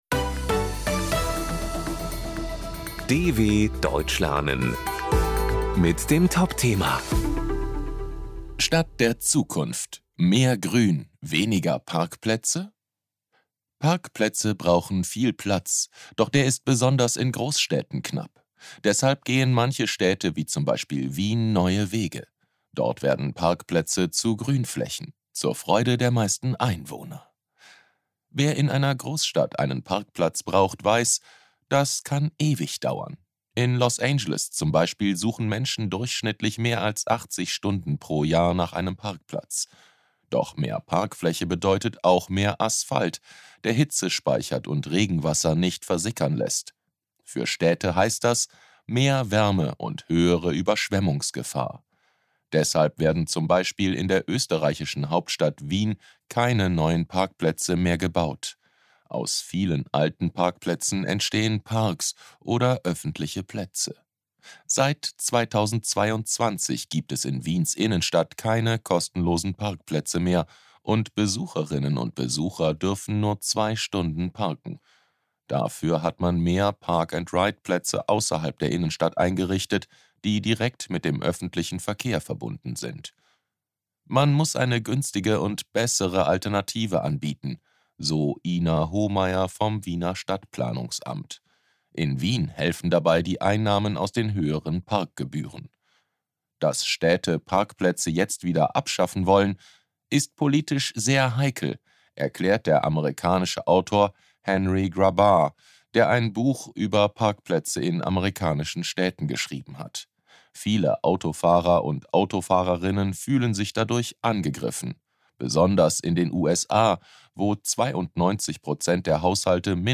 B1 | Deutsch für Fortgeschrittene: Deutsch lernen mit Realitätsbezug: aktuelle Berichte der Deutschen Welle – leicht verständlich und mit Vokabelglossar.